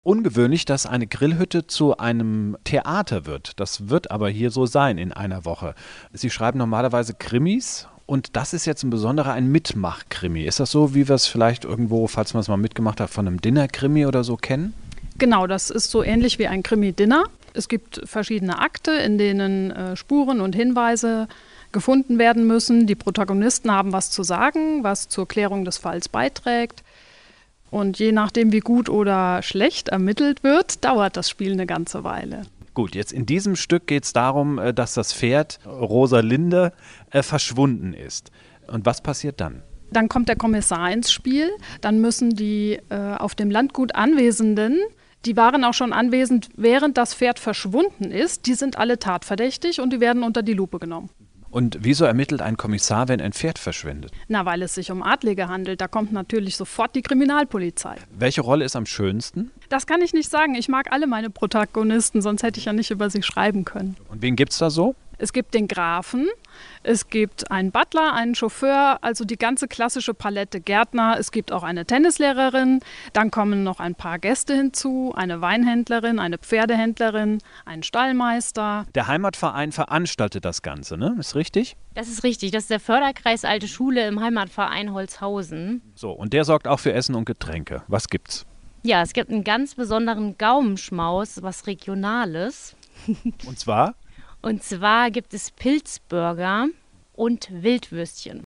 stz---1102-mitmach-krimi-interview-v1.mp3